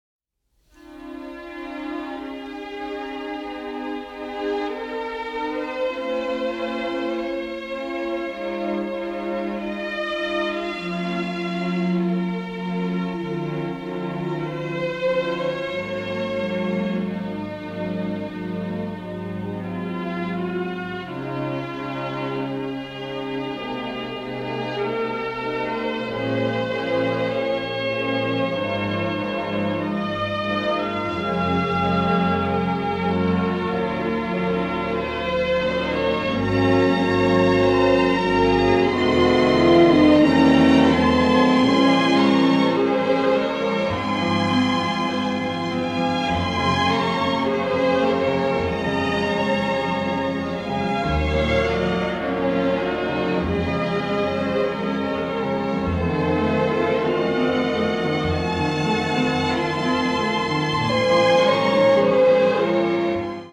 score is lush and full of kaleidoscopic orchestral color